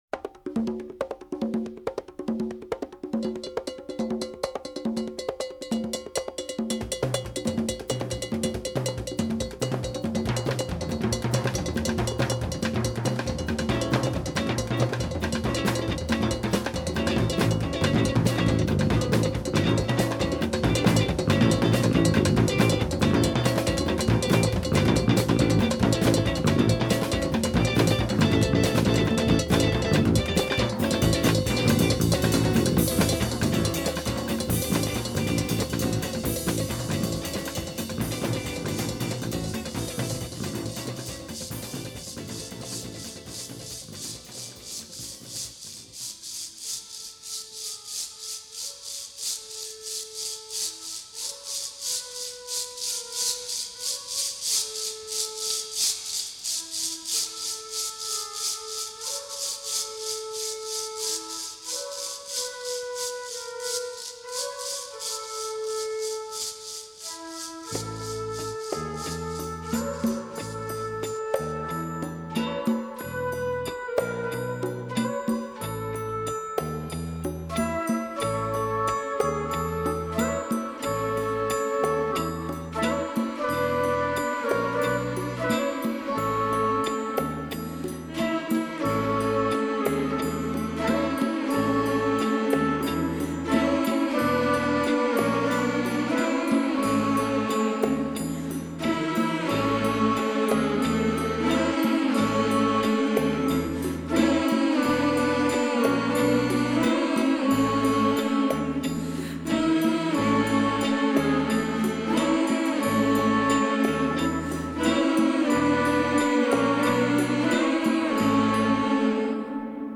Этот на слух получше звучит